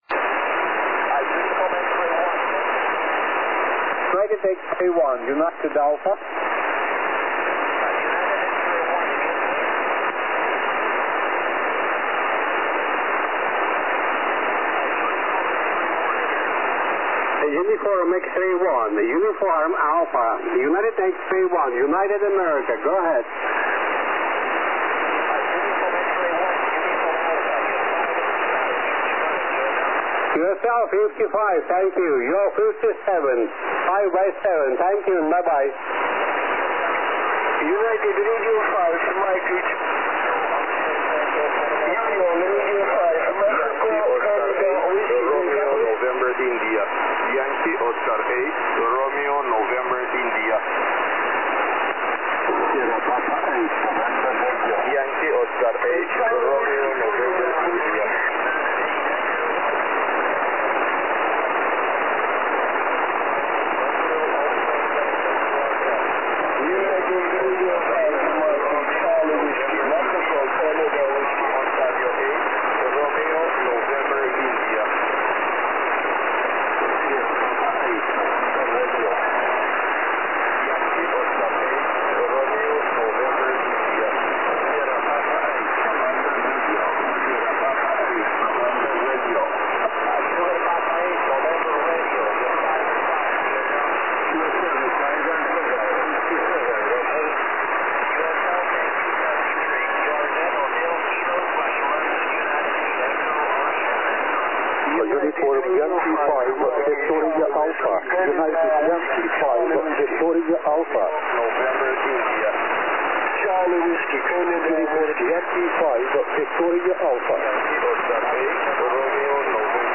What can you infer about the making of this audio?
160 SSB